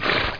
horse2.mp3